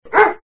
Ladrido
Sonido del ladrido de un perro
perro
Sonidos: Animales